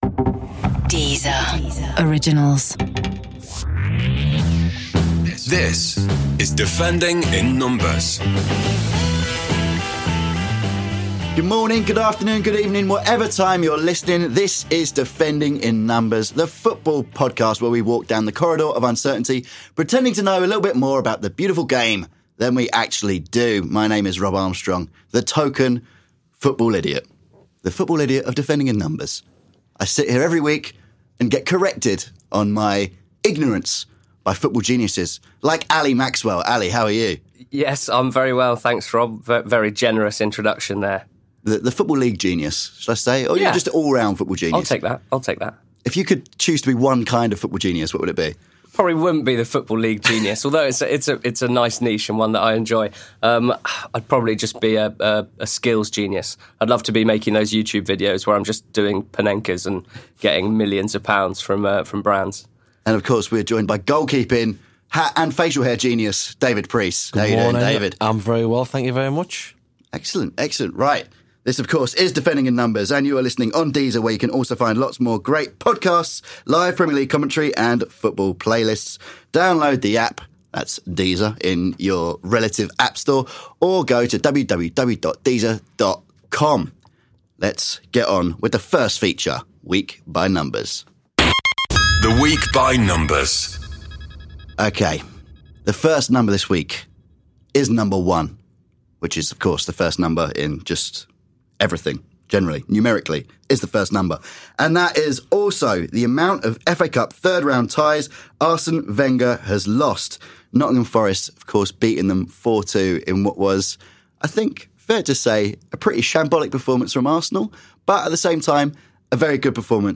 joined in the studio